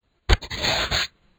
door-old1.wav